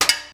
Percussive FX 11 ZG